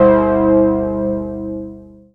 MORLOCKS C3.wav